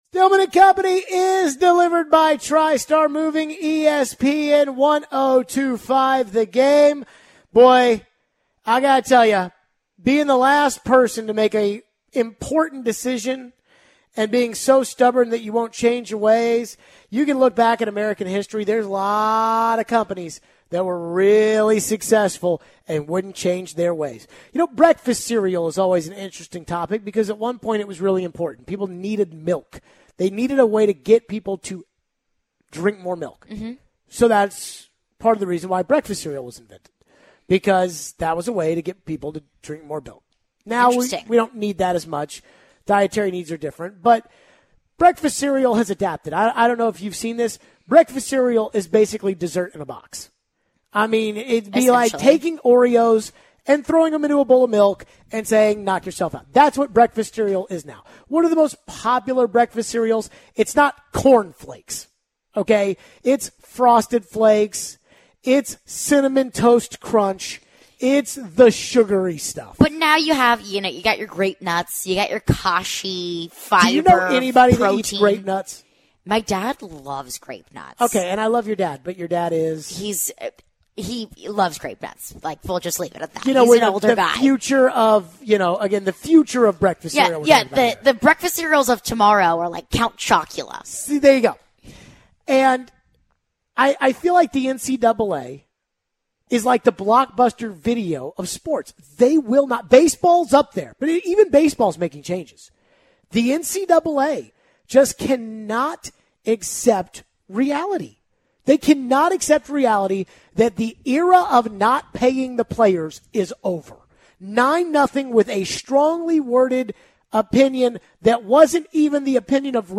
We take your phones. Our 'CaroLINES' betting segment and we wrap up with some thoughts on Vandy vs Stanford tonight.